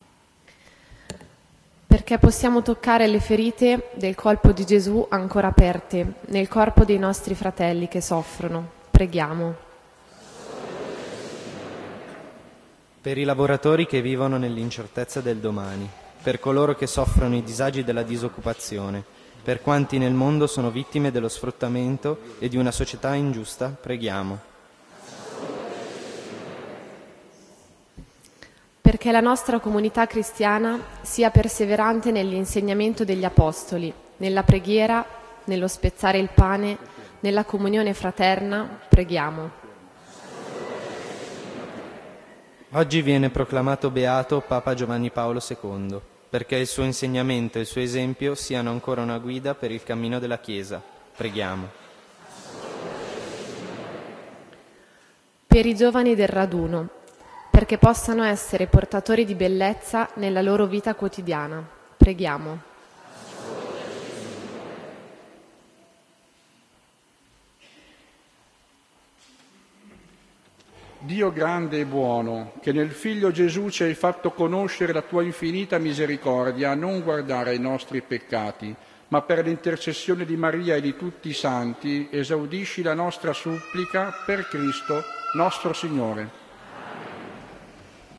Raduno Giovani 2011 S.Messa – audio
12-Preghiera_dei_fedeli.ogg